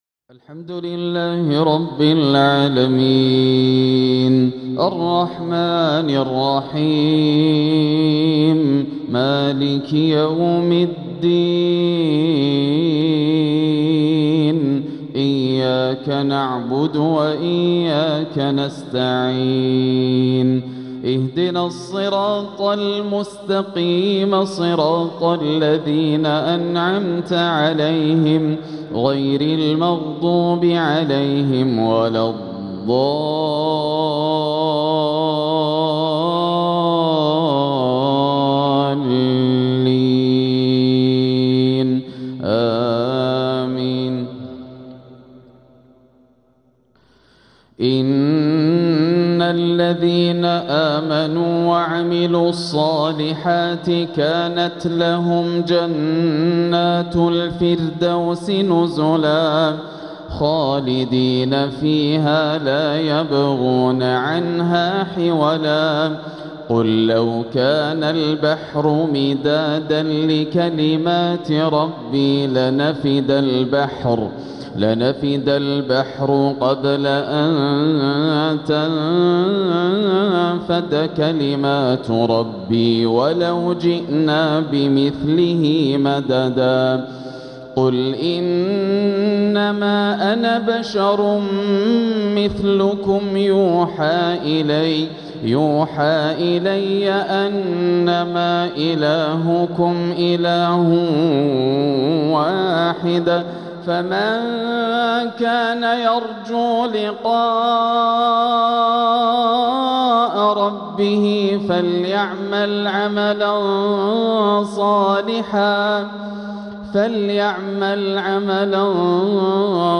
تلاوة لخواتيم سورتي الكهف و مريم | عشاء الاثنين 9-3-1447هـ > عام 1447 > الفروض - تلاوات ياسر الدوسري